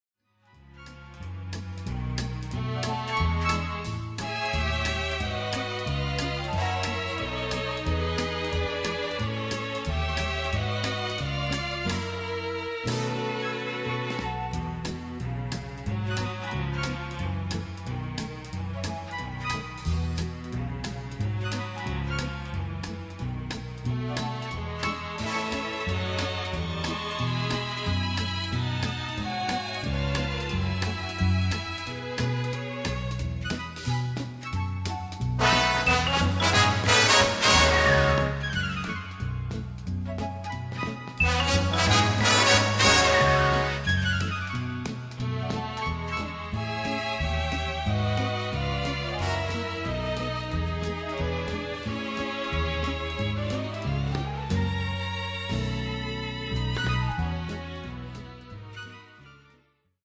Category: Broadway, Film and Shows